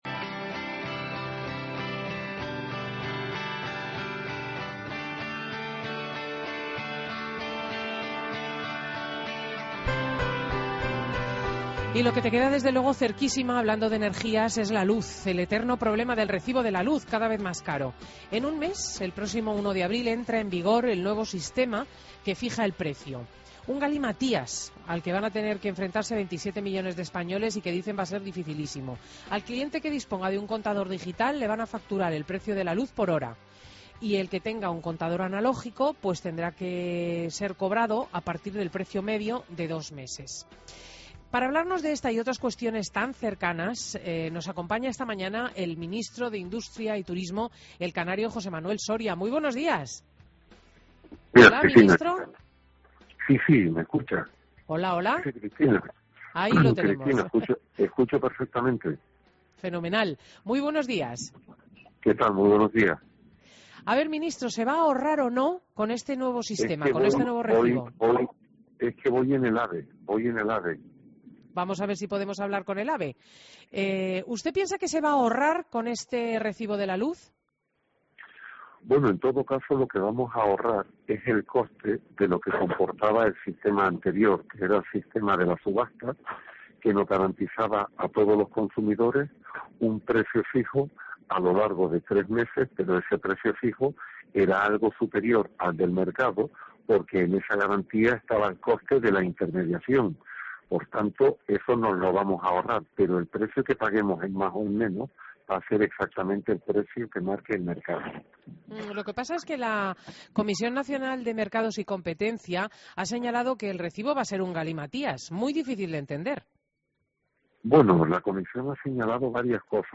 Entrevista a José Manuel Soria en Fin de Semana COPE